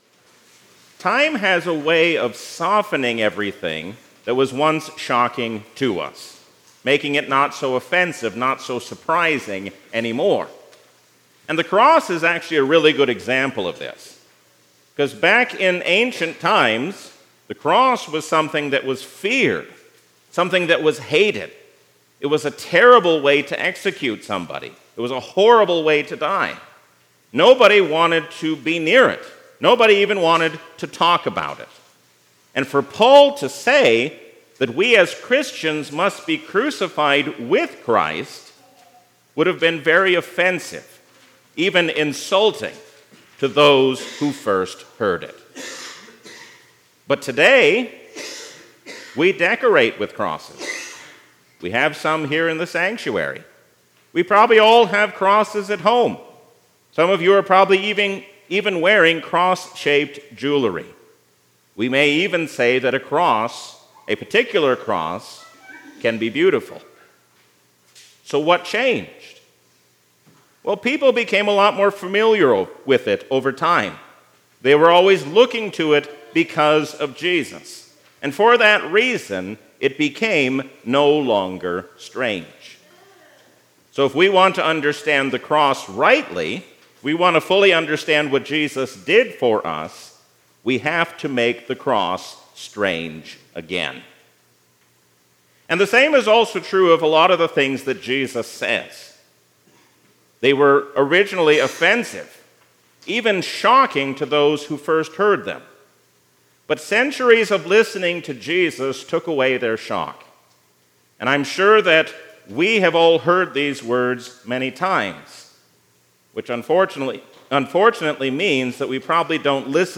A sermon from the season "Trinity 2023."